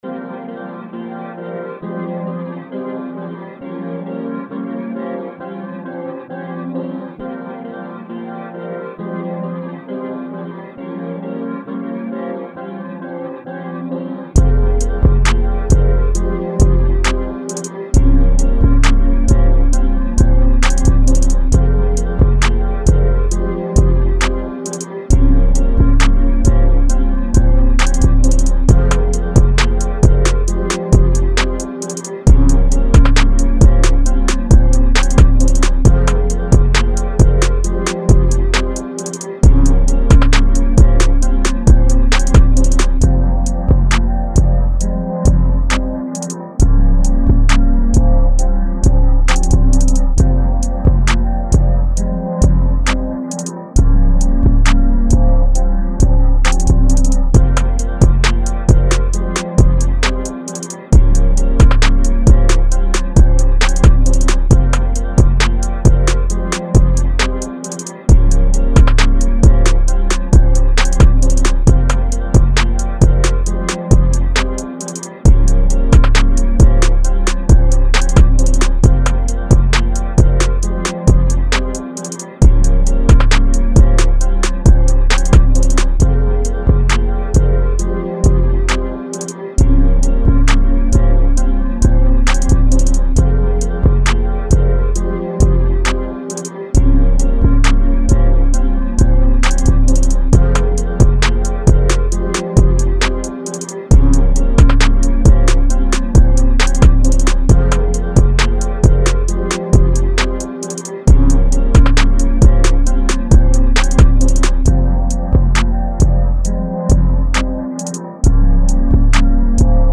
Rap
fminor